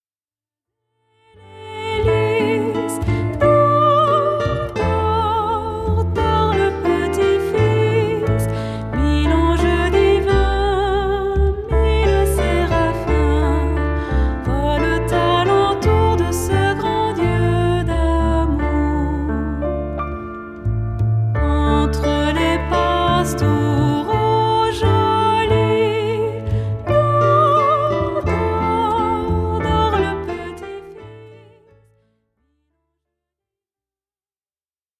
Les grands classiques de Noël
voix cristalline